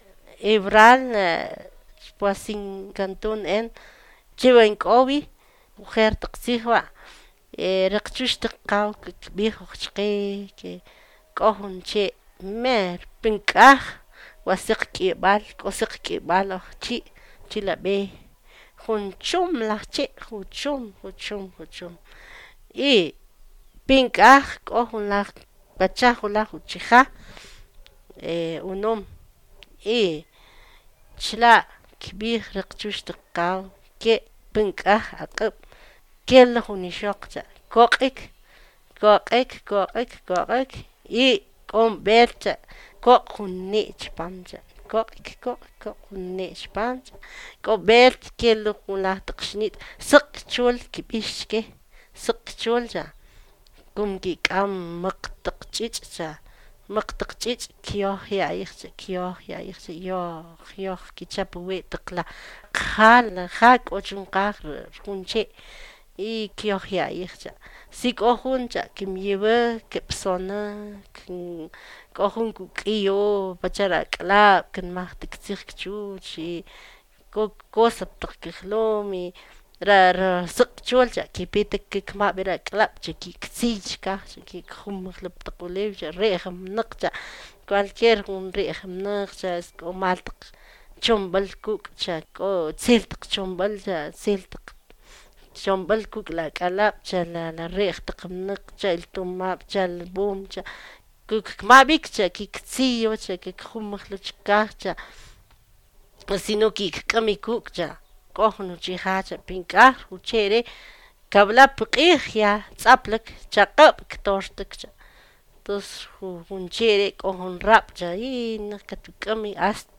(Note that the audio files linked here have been edited to remove errors and repetitions, but the complete, original files, exactly as recorded, can be accessed through through the Archive of the Indigenous Languages of Latin America.)